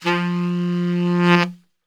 F 1 SAXSWL.wav